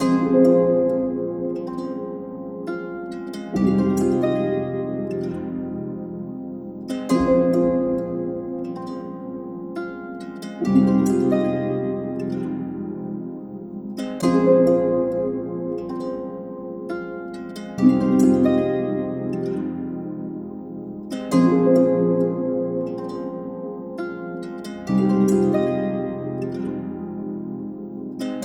14 Trap Loops created in the style of: Drake, Future, Travis Scott, A$ap Ferg, 21 Savage, Lil Baby, 2 Chainz, Juice WRLD and More!
Perfect for Trap, but works well with R&B, Pop and similar genres too.
• High-Quality Trap Samples 💯